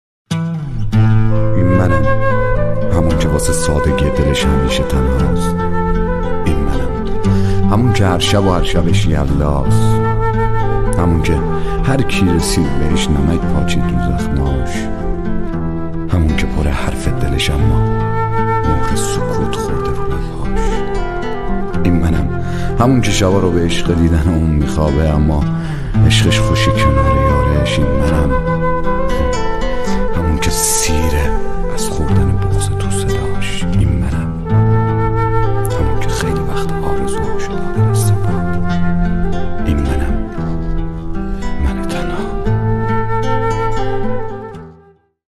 دکلمه شب یلدا ؛ ۹ متن دکلمه در مورد شب یلدا - ستاره
دکلمه‌های مخصوص شب یلدا، با بیانی شیوا به توصیف طولانی‌ترین شب سال می‌پردازند.